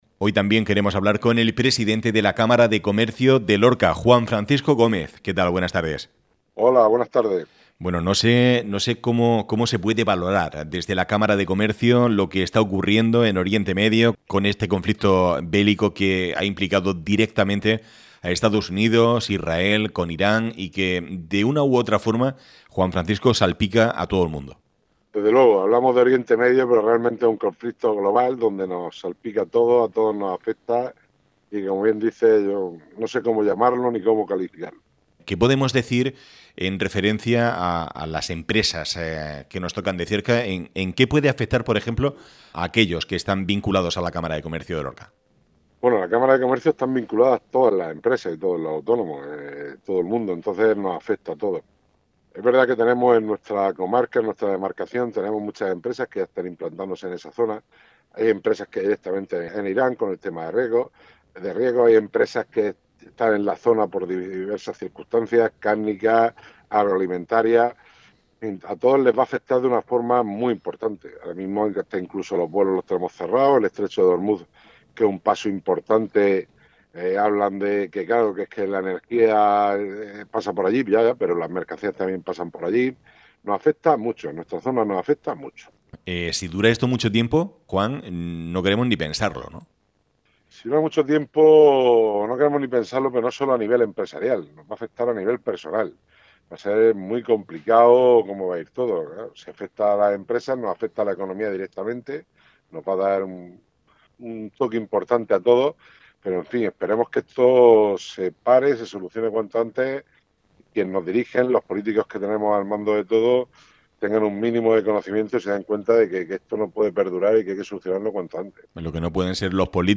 ha intervenido en el magazine de Área Lorca Radio